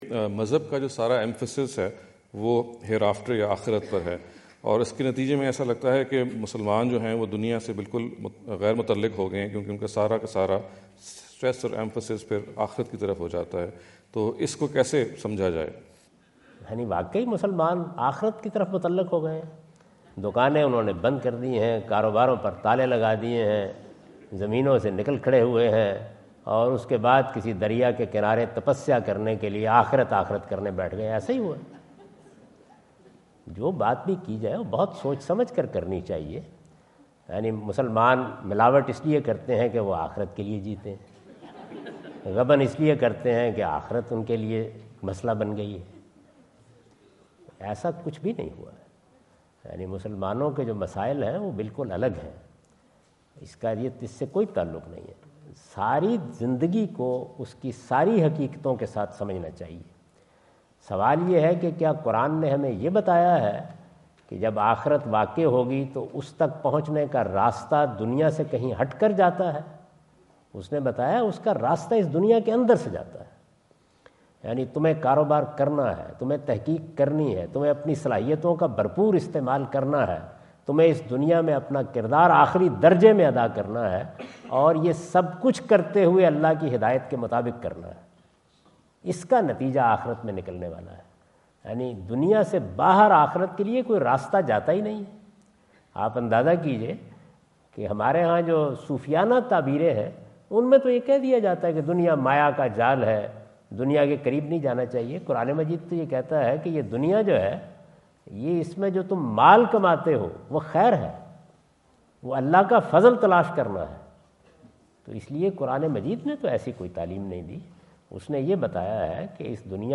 Javed Ahmad Ghamidi answer the question about "Does Religion Emphasise Hereafter Only?" asked at The University of Houston, Houston Texas on November 05,2017.